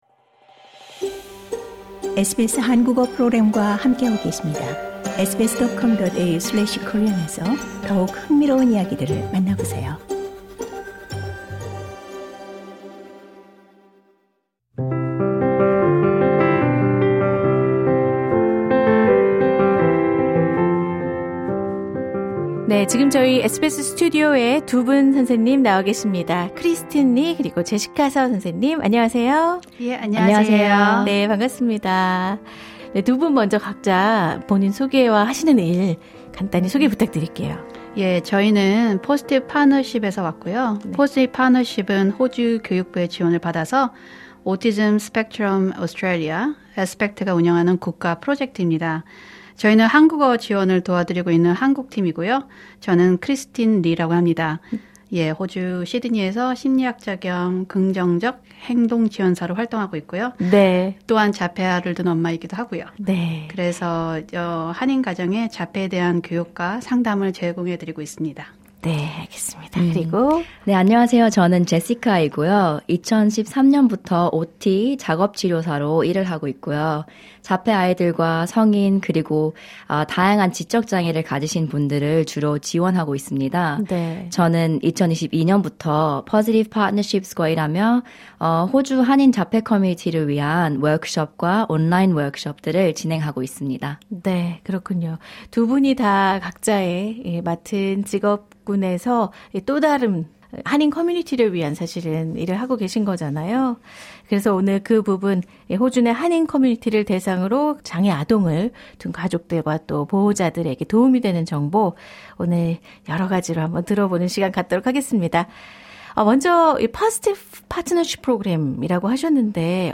인터뷰: “자폐는 고쳐야 할 대상이 아닙니다” 한인 전문가들이 전하는 자폐 아동 지원의 첫걸음